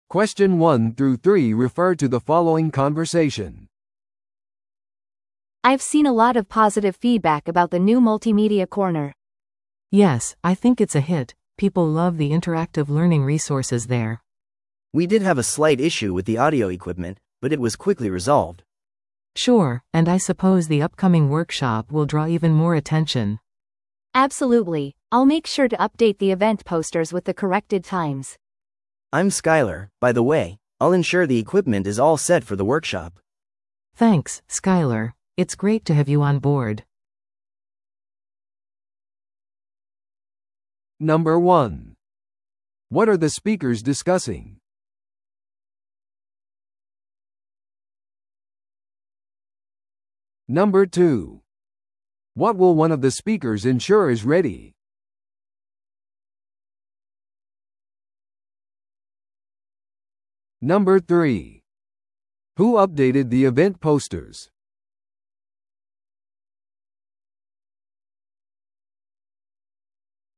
正解 B: The woman who spoke first.